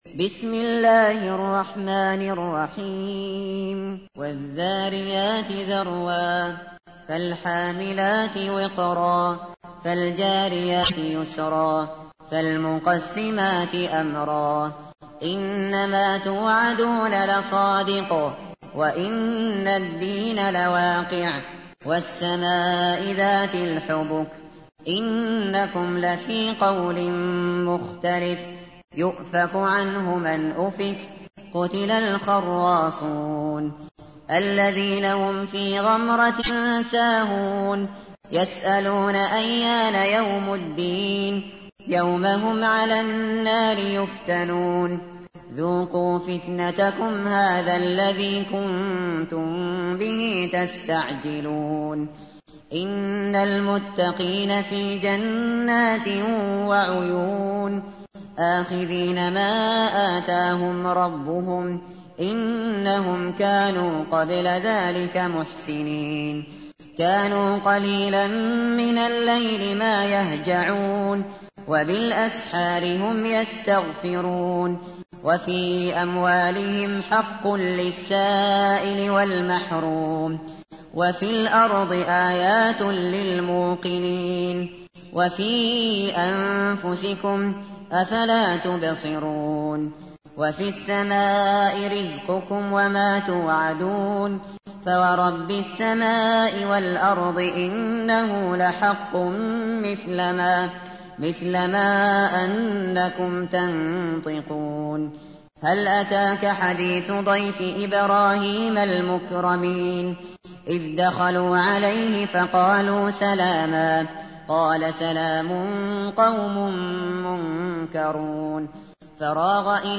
تحميل : 51. سورة الذاريات / القارئ الشاطري / القرآن الكريم / موقع يا حسين